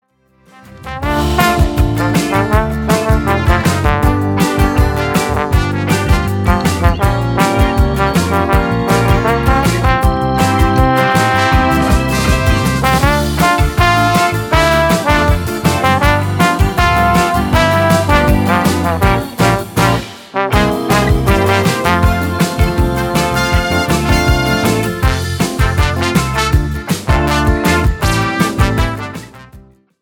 POP  (02.53)